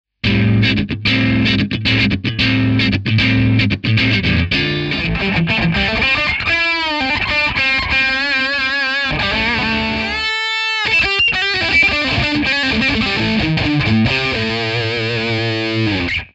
BEHRINGER / TM300はチューブアンプサウンドを再現可能なギター用エフェクターです。クリーンサウンドからクランチサウンド、過激なディストーションサウンドまで対応します。